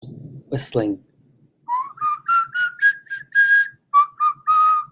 speech_whistling2.wav